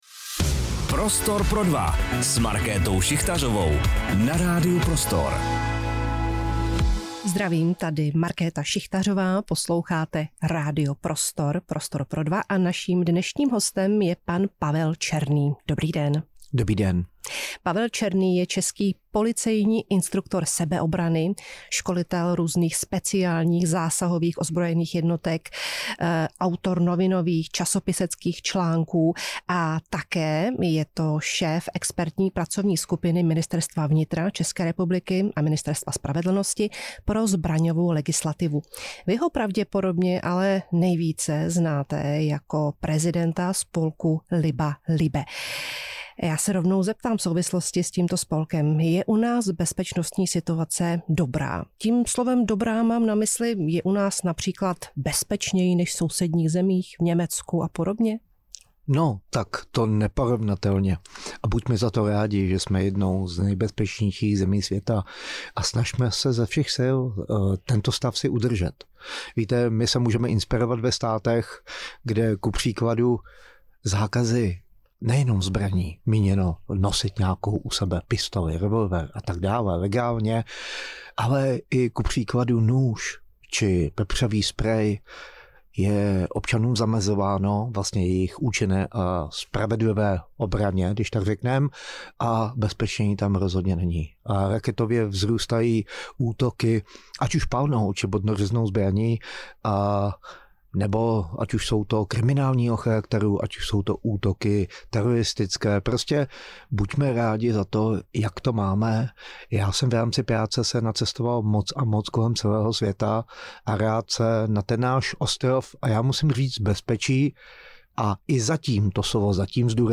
Dozvěděli jsme se, proč se Česko řadí mezi nejbezpečnější země světa, ale také o výzvách, které přicházejí s legislativními tlaky ze zahraničí. Rozhovor moderuje Markéta Šichtařová.